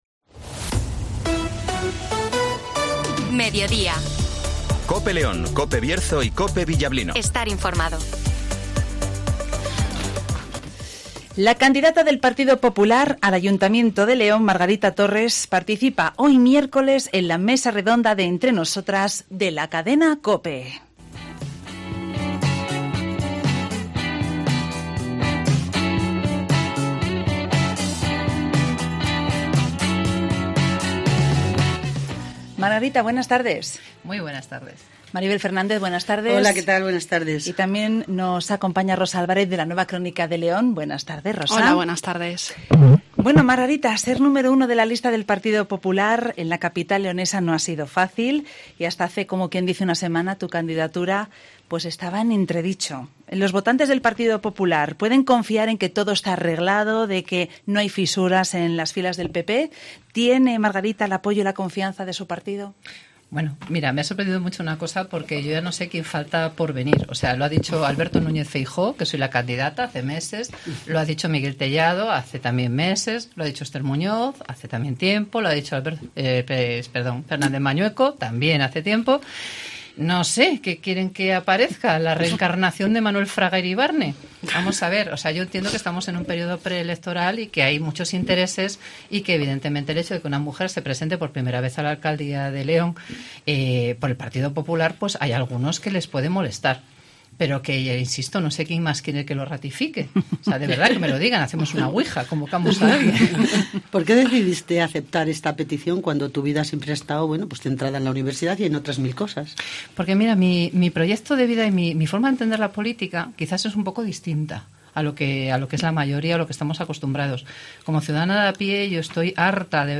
participa hoy miércoles en la mesa redonda de “Entre Nosotras” de la Cadena Cope.